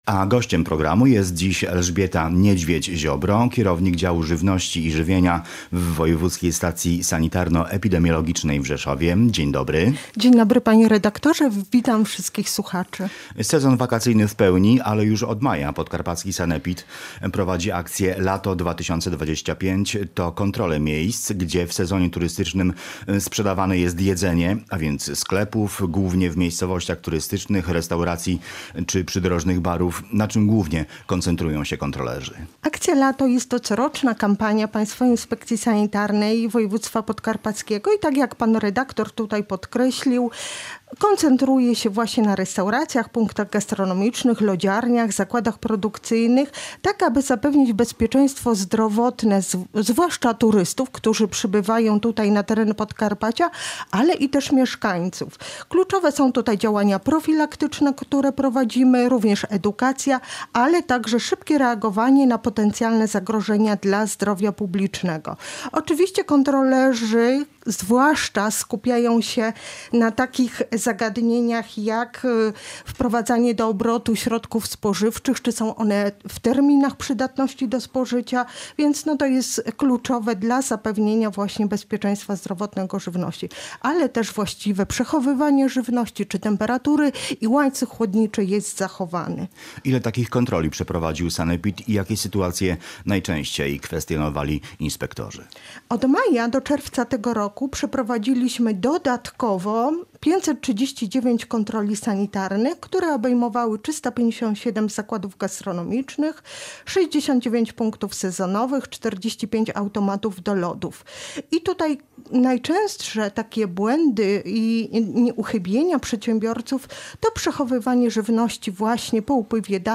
Jeśli zaś natrafimy na brudne obrusy, czy niedomyte sztućce to można tylko wyobrazić sobie co dzieje się na zapleczu, do którego klient nie ma wstępu – dodaje gość Polskiego Radia Rzeszów. Akcja Sanepidu „Lato 2025” potrwa do końca sierpnia.